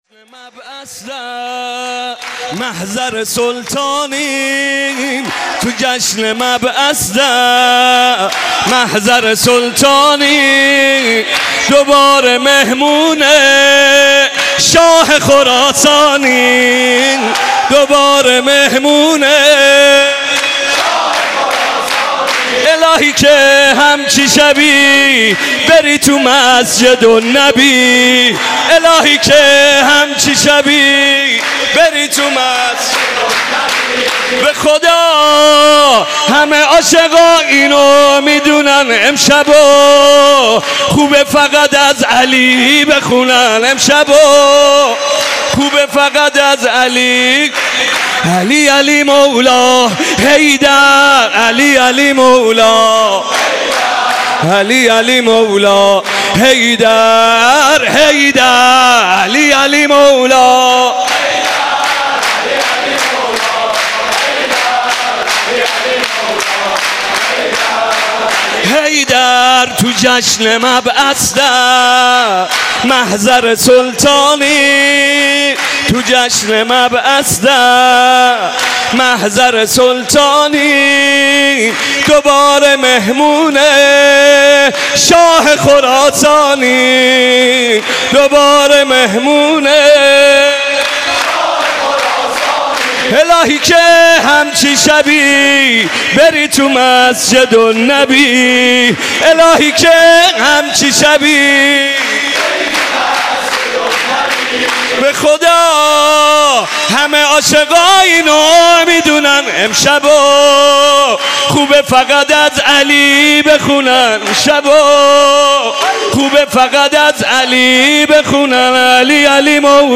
مناسبت : مبعث رسول اکرم صلی‌الله‌علیه‌وآله
قالب : سرود